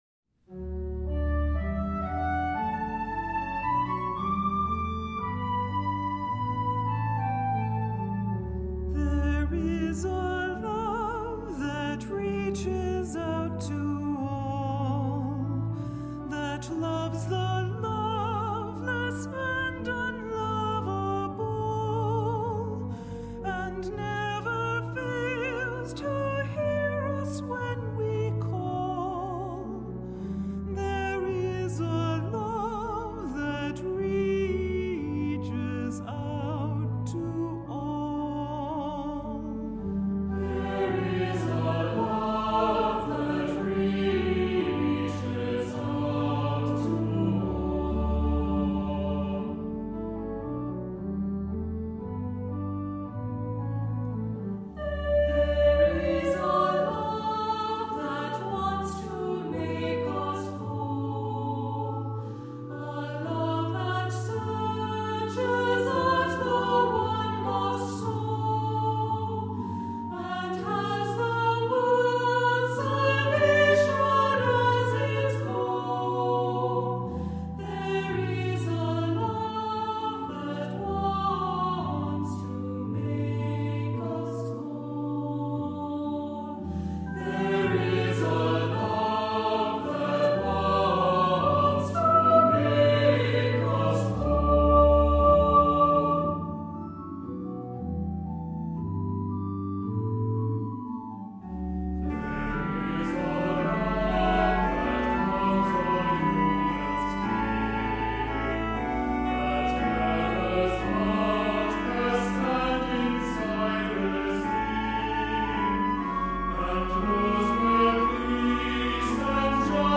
Voicing: Cantor, assembly